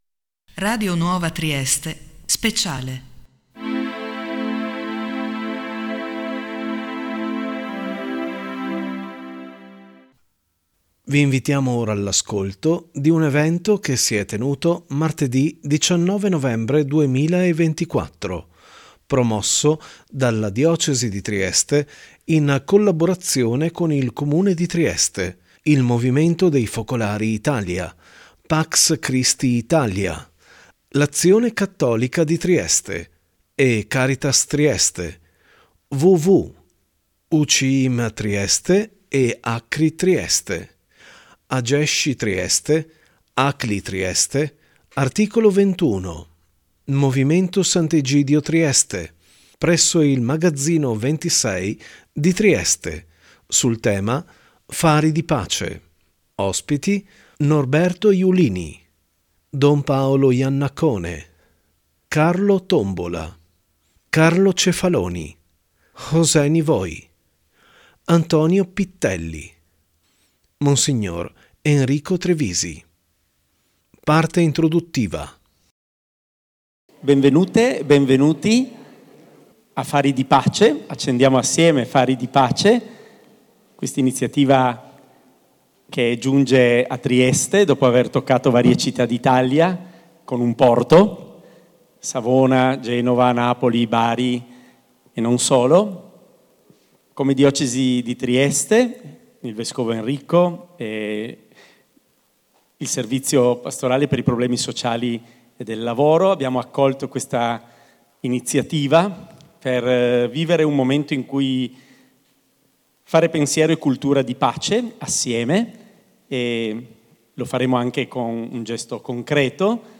un evento promosso dalla Diocesi di Trieste
presso il Magazzino 26 di Trieste